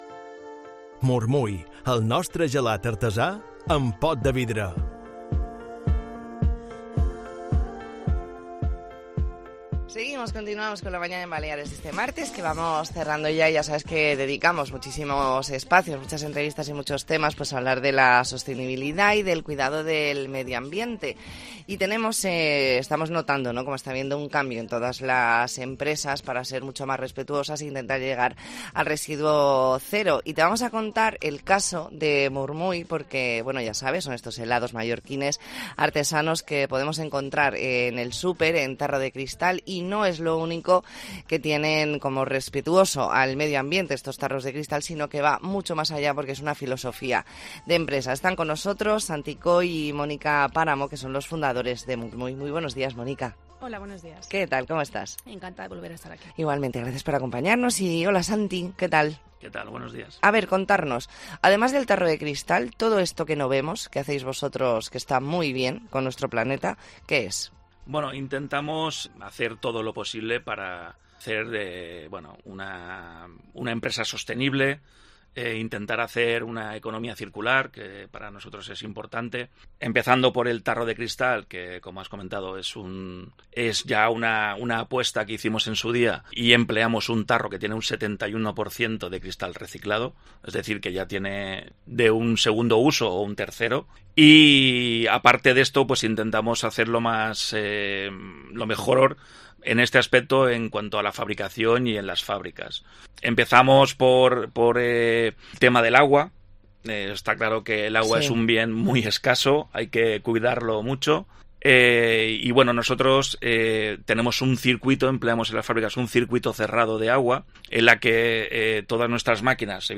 Entrevista en La Mañana en COPE Más Mallorca, martes 28 de noviembre de 2023.